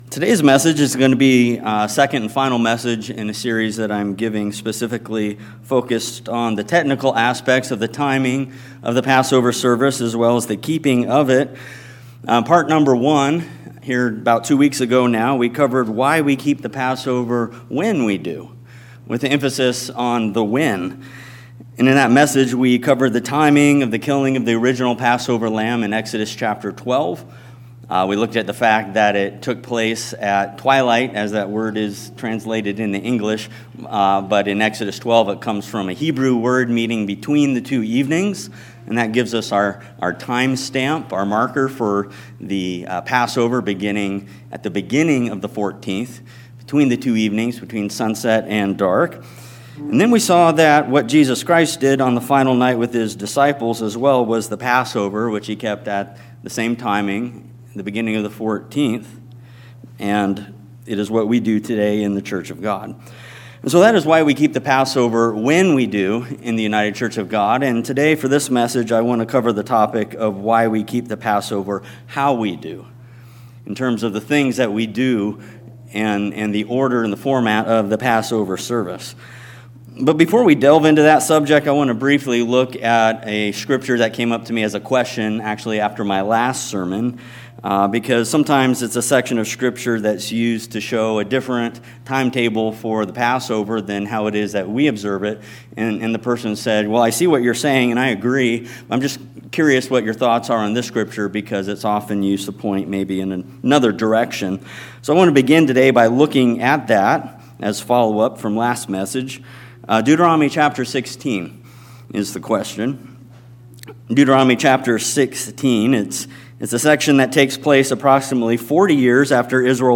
This sermon asks and answers four important questions about the Passover observance we keep; helping us to see the direct connection between what the scripture instructs and what we actually do on that night.